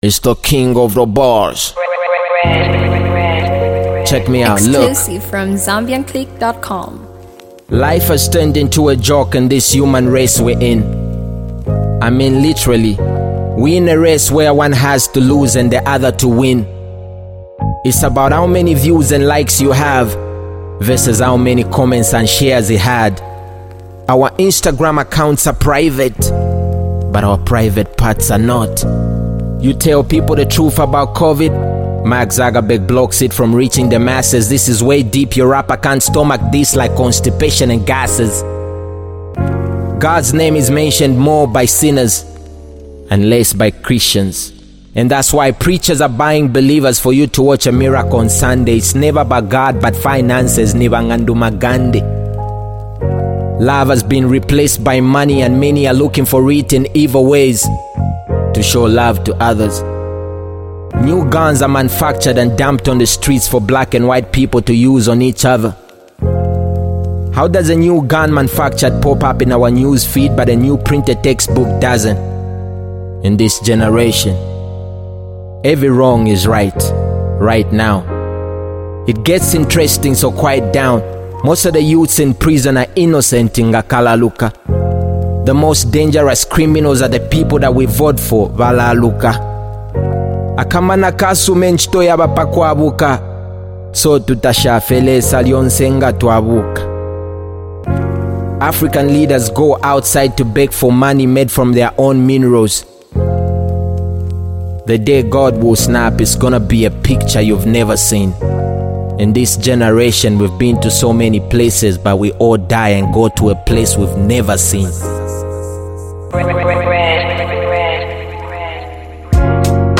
hip-hop freestyle
On this hardcore hip-hop freestyle